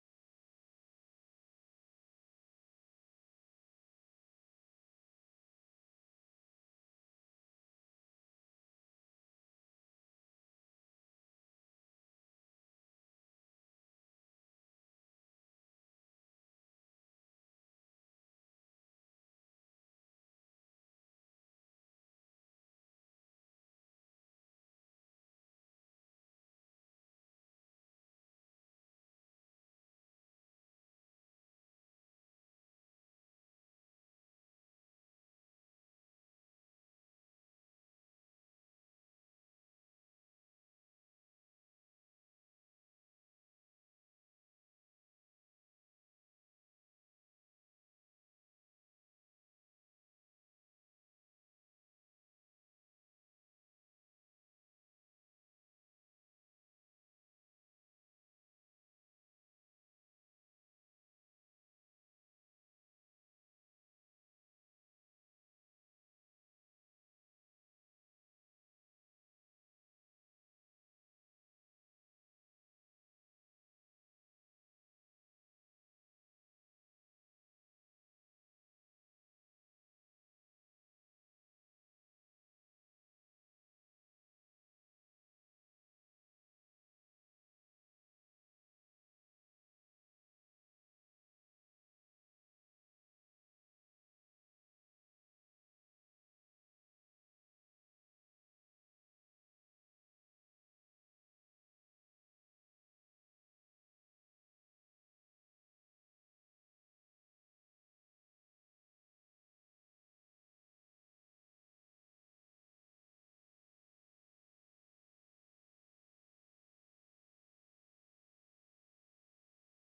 Live Tuesdays 9-1